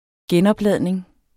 Udtale [ ˈgεnʌbˌlæðˀneŋ ]